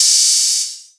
DDW3 OPN HAT 1.wav